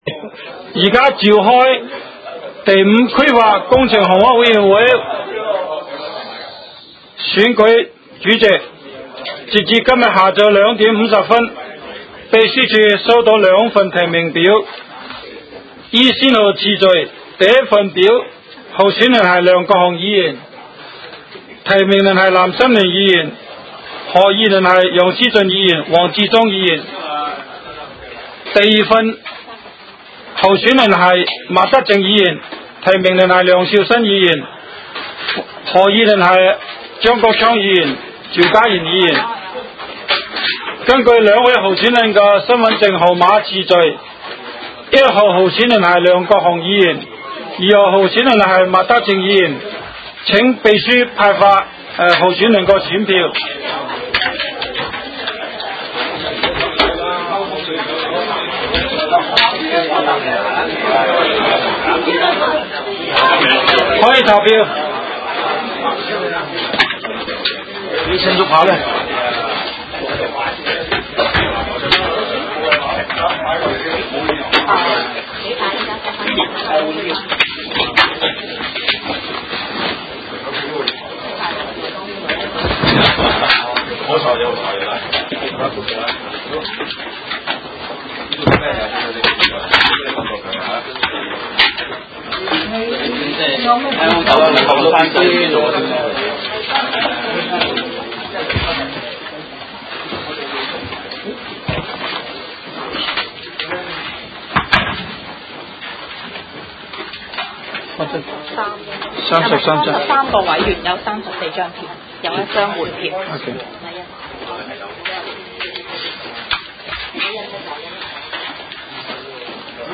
委员会会议的录音记录
地点: 香港西湾河太安街29号 东区法院大楼11楼 东区区议会会议室